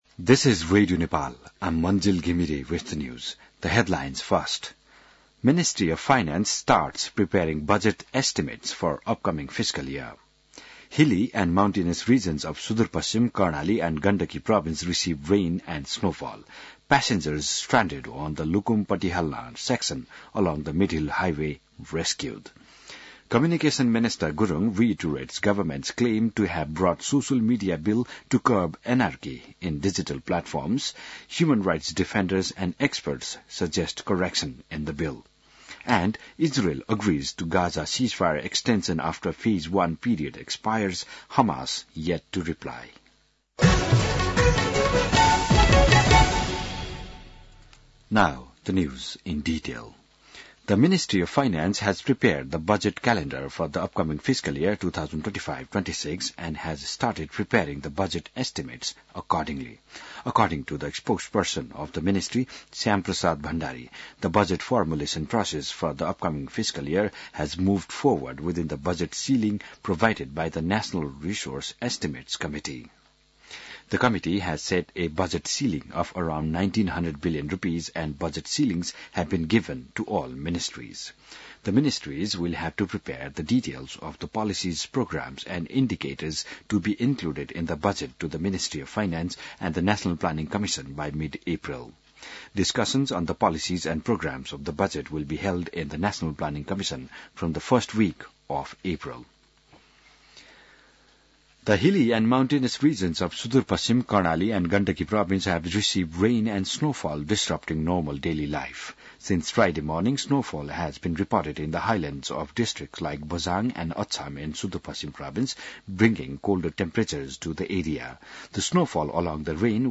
बिहान ८ बजेको अङ्ग्रेजी समाचार : १९ फागुन , २०८१